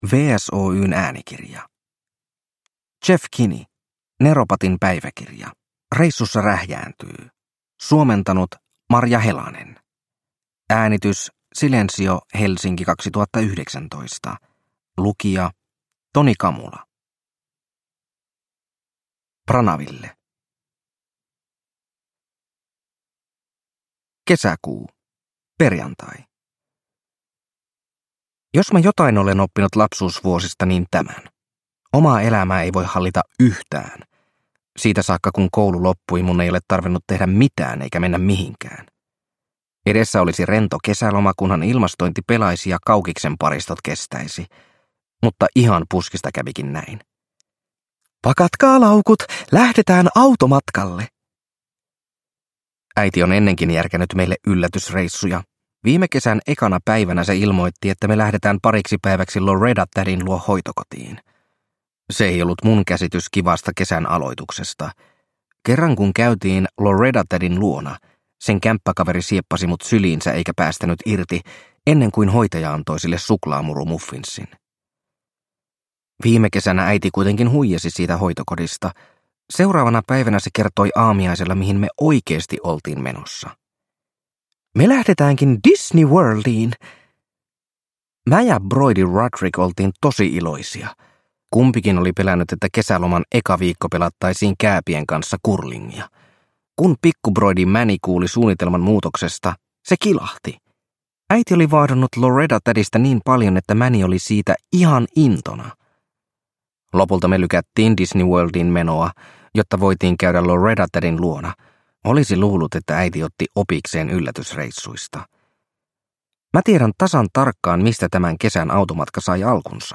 Neropatin päiväkirja: Reissussa rähjääntyy – Ljudbok – Laddas ner